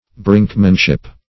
brinkmanship \brink"man*ship\, brinksmanship \brinks"man*ship\n.